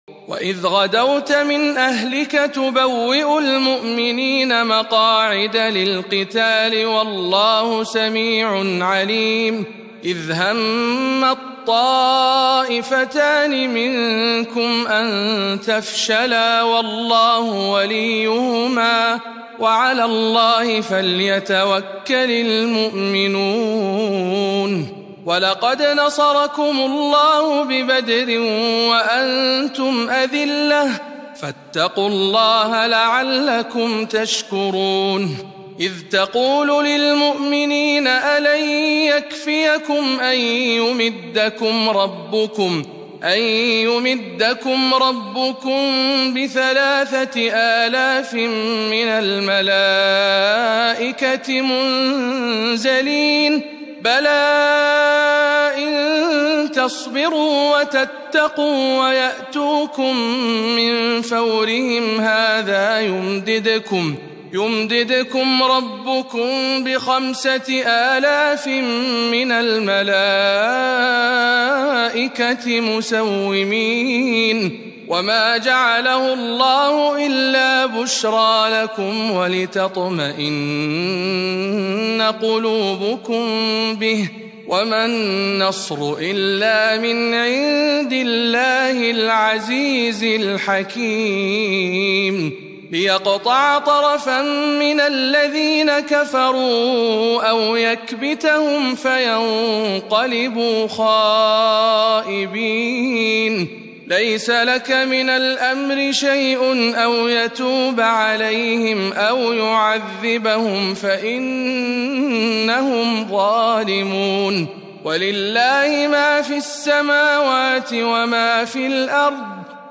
تلاوة مميزة من سورة آل عمران